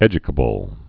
(ĕjə-kə-bəl)